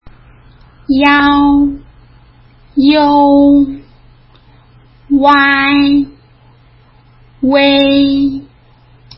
真ん中の音を強く発音
iao ｢ィアォ｣と発音するイメージ。
iou ｢ィオゥ｣と発音するイメージ。
uai ｢ゥアィ｣と発音するイメージ。
uei ｢ゥエィ｣と発音するイメージ。